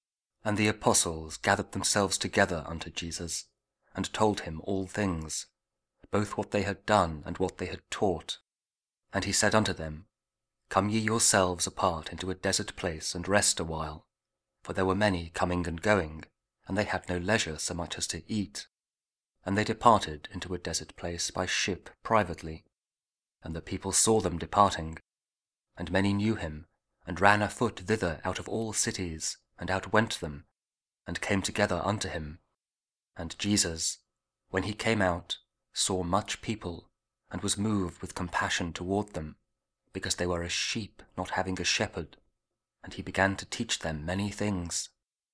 Mark 6: 30-34 – Week 4 Ordinary Time, Saturday (Audio Bible KJV, Spoken Word)